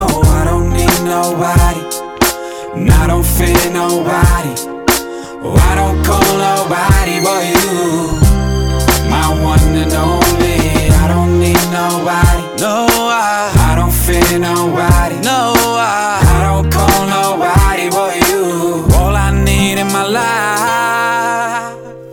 • Качество: 205, Stereo
Завораживающие